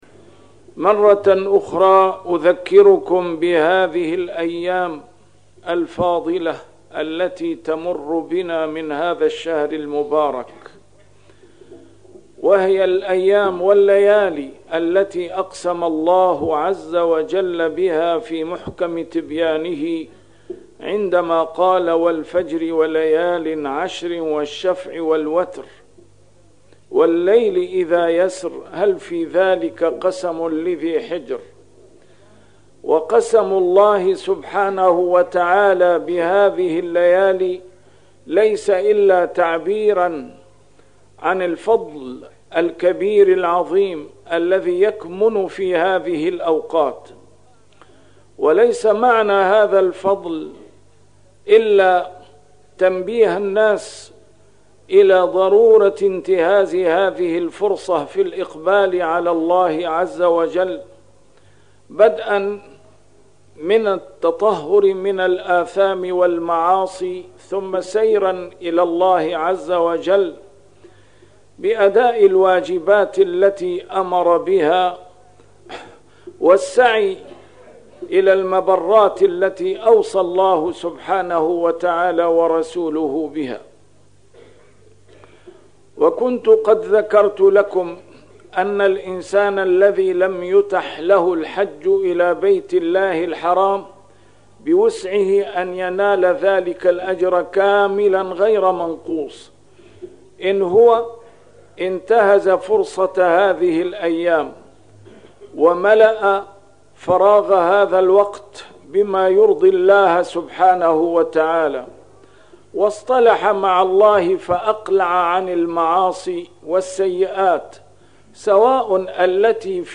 A MARTYR SCHOLAR: IMAM MUHAMMAD SAEED RAMADAN AL-BOUTI - الخطب - أذكركم بيوم عرفة